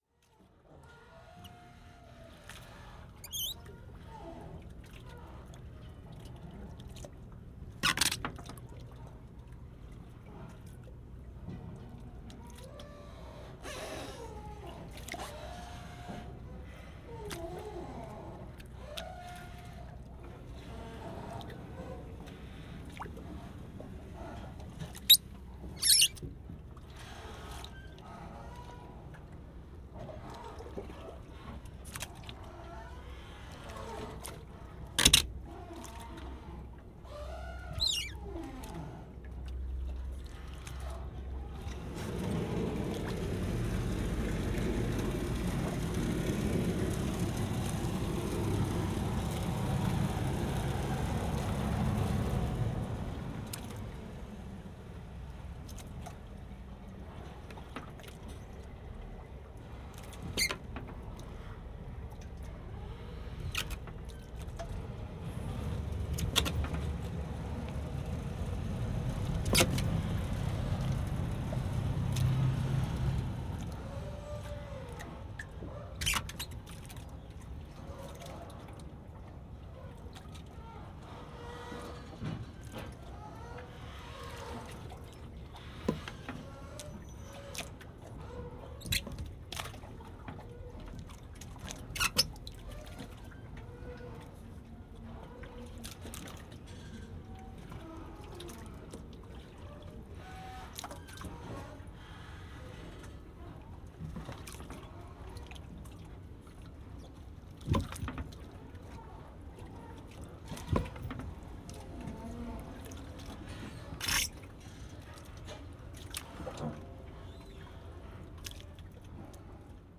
Unprocessed Field Recordings 1999–2007, Germany and Finland.
3. Hamburg, Germany
Metallic creaks, groans, squeaks, thuds and rattles dominate the foreground, while the background often provides a subtle layer of sound that gives the recordings additional depth.
This recording is a delicate combination of the mechanical and the natural.
All are of the highest technical quality and possess a richness that makes the listening experience a real pleasure.